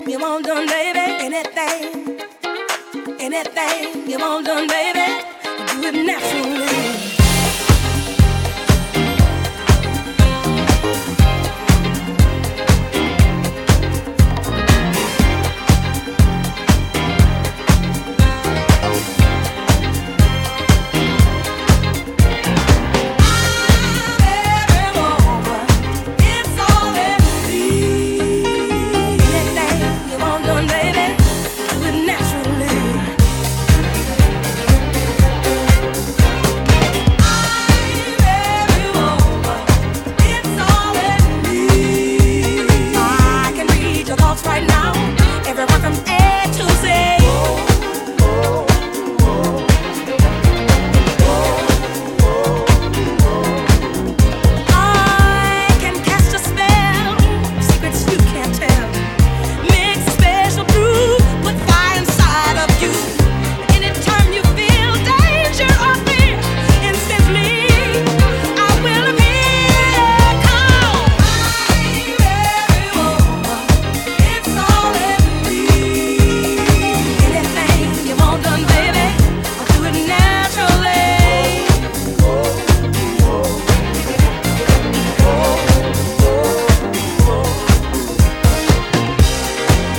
dancefloor anthem
respectfully reworked and rearranged from the master tapes
Disco reworks